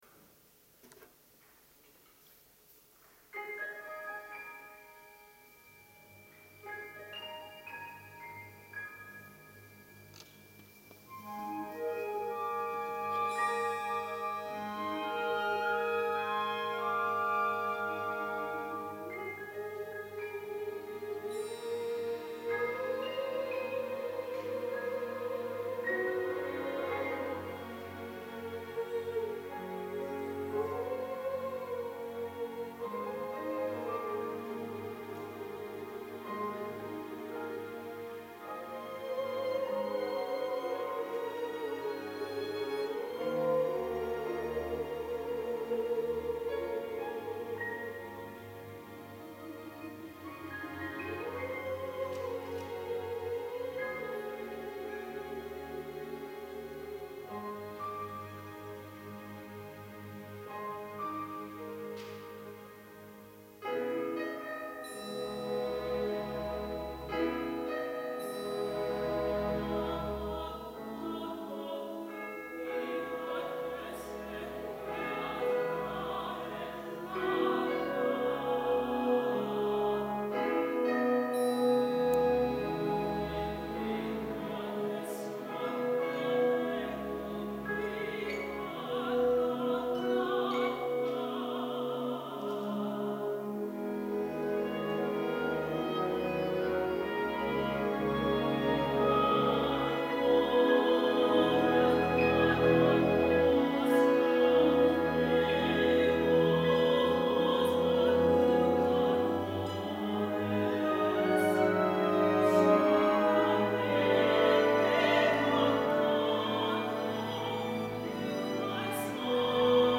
Symphonic Song Cycle